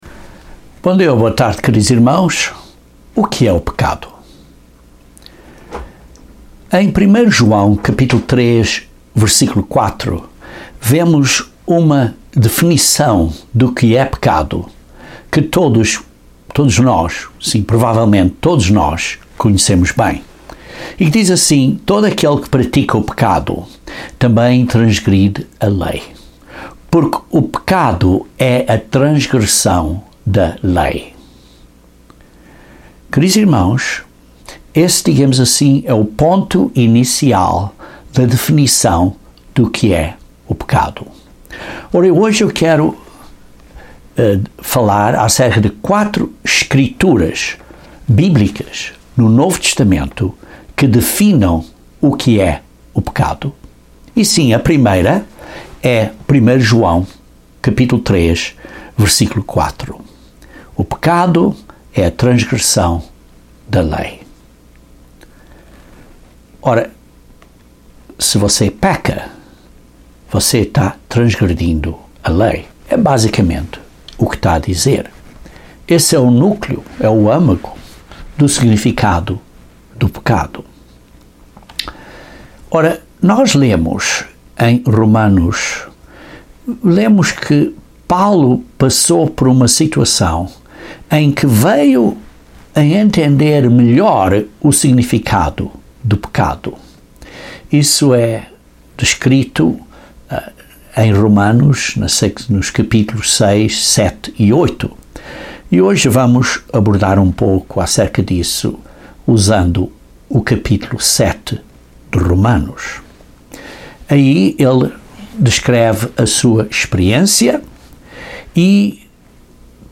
Devemos entender o que é o pecado para que possamos identificá-lo adequadamente em nossas vidas. Este sermão examina quatro escrituras do Novo Testamento que definem o pecado e as concretiza em um contexto diário.